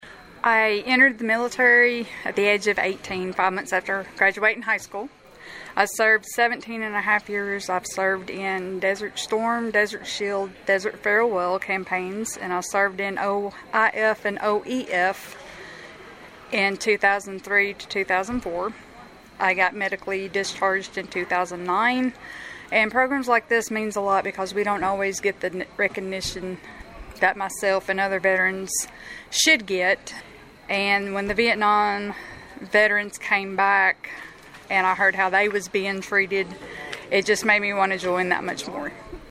The Pleasant Grove Baptist Church located on Hopkinsville Road in Princeton recognized Veterans on Saturday night with a fish meal prepared by the church members.